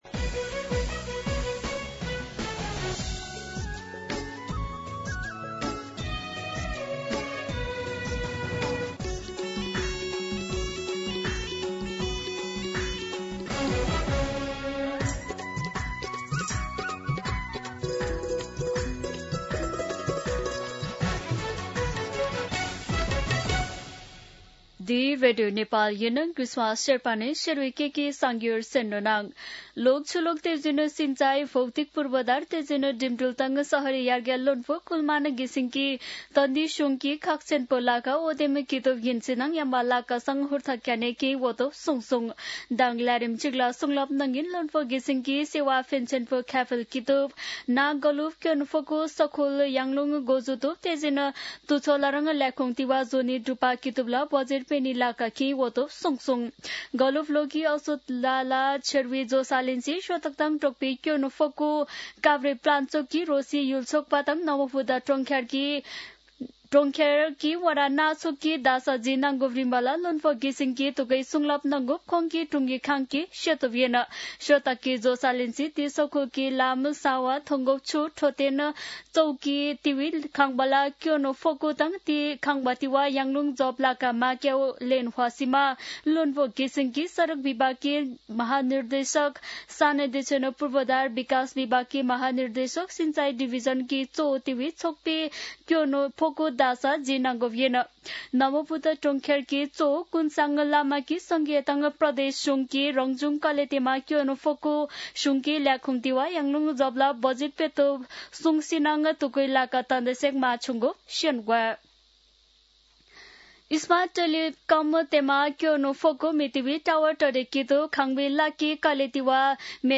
शेर्पा भाषाको समाचार : १३ मंसिर , २०८२
Sherpa-News-2.mp3